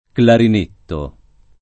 clarinetto [ klarin % tto ] s. m.